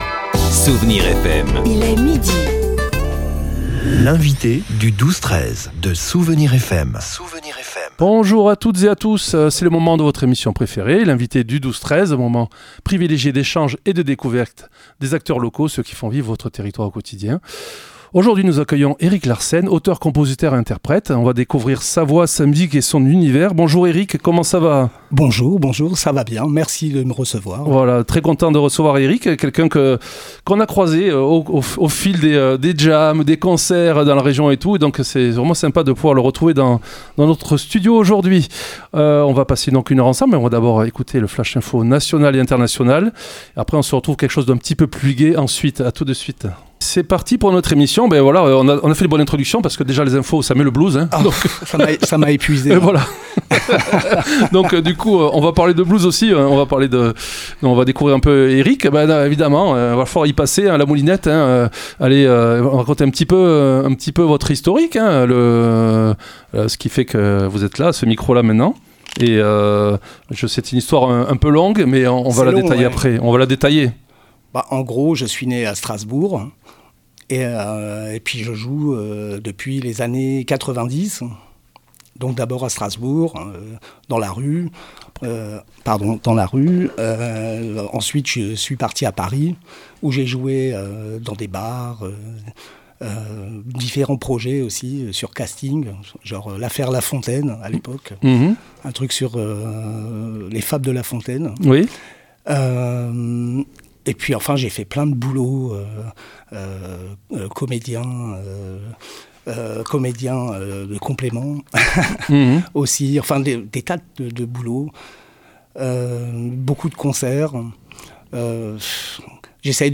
Cet artiste a un goût prononcé pour le blues, mais pas seulement !
Il nous a interprété deux titres en direct, révélant une maîtrise impressionnante de la guitare et de l’harmonica. À écouter dans ce podcast : vous allez adorer son grain de voix et son style bluesy à souhait.